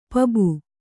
♪ pabu